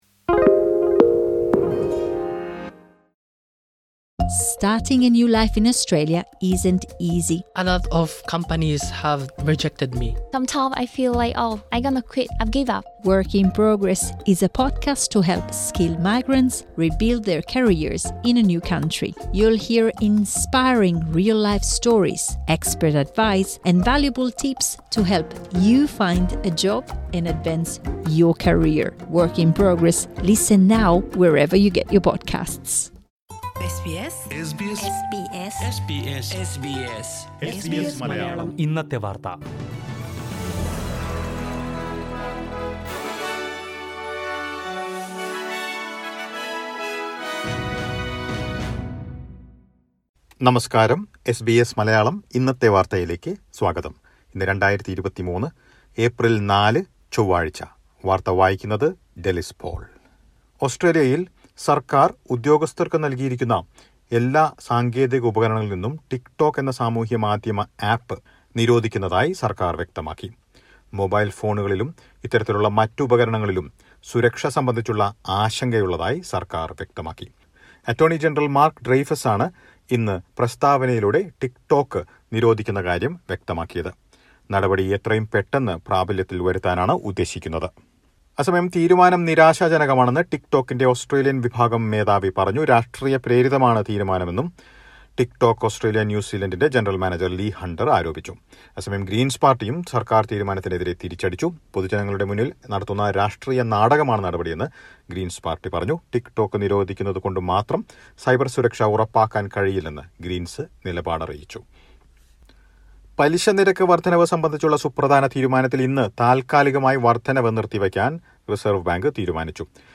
2023 ഏപ്രിൽ നാലിലെ ഓസ്ട്രേലിയയിലെ ഏറ്റവും പ്രധാന വാർത്തകൾ കേൾക്കാം...